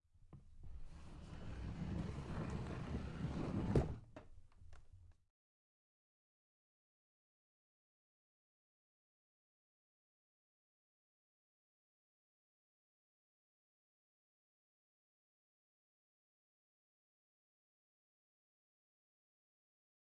抽屉关闭
描述：厨房抽屉被关闭
Tag: 关闭 厨房 抽屉